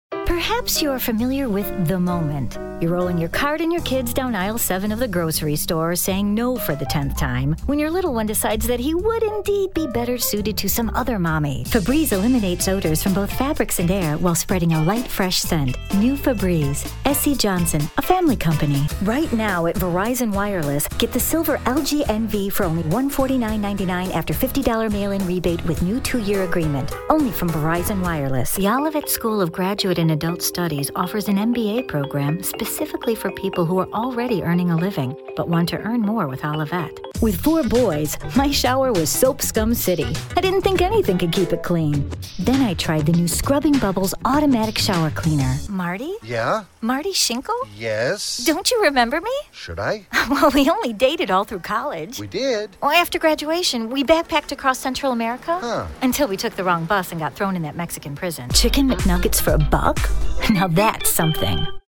Clients love her genuine sound and accomplished acting abilities.
Sprechprobe: Werbung (Muttersprache):